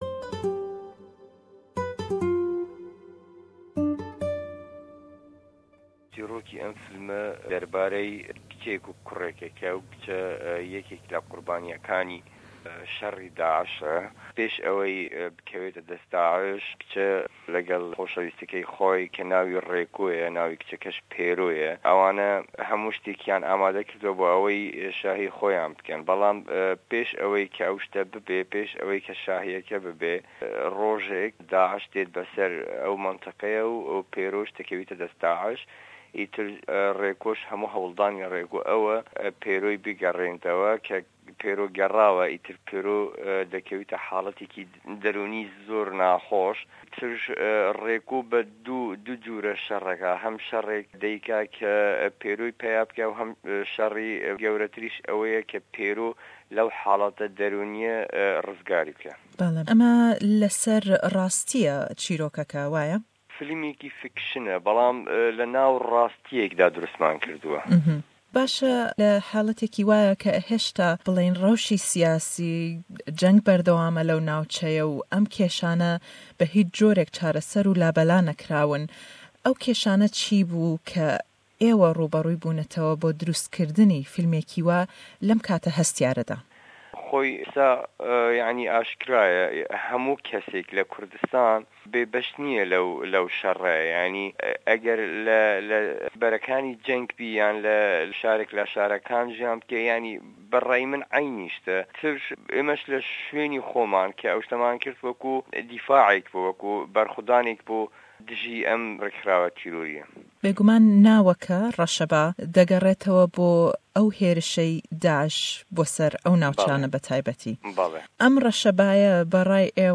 hevpeyvîne